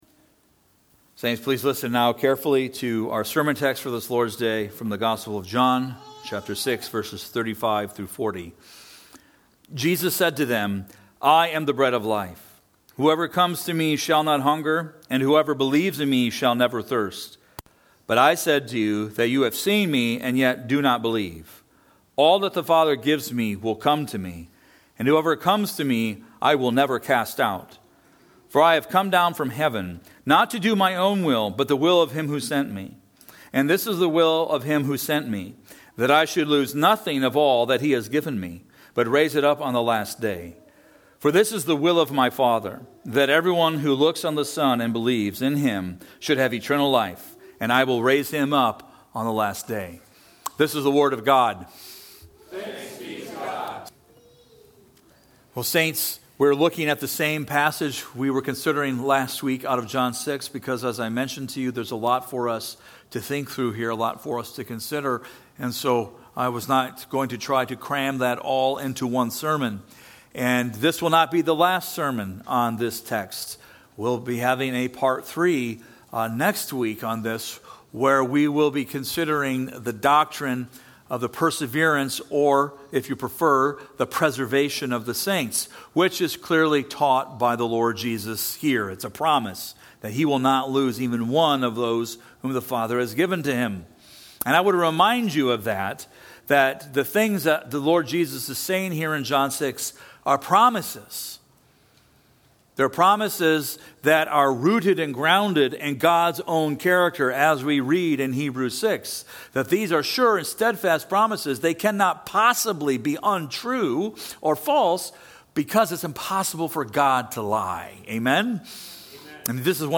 TRC_Sermon-3.2.25.mp3